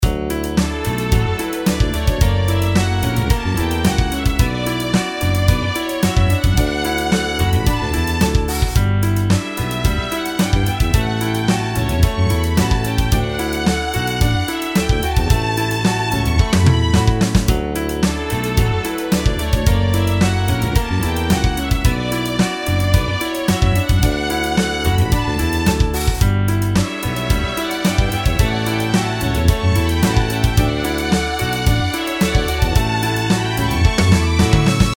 音楽ジャンル： ポップス
楽曲の曲調： SOFT
シチュエーション： 明るい